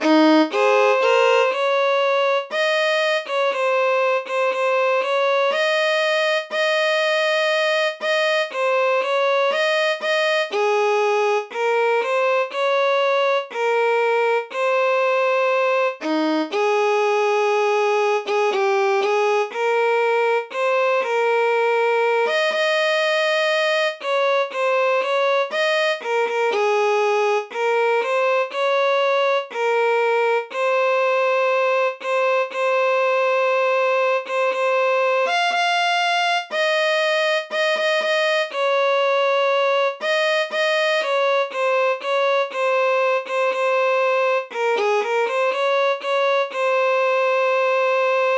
tenor
y'varech'cha_tenor.mp3